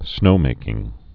(snōmākĭng)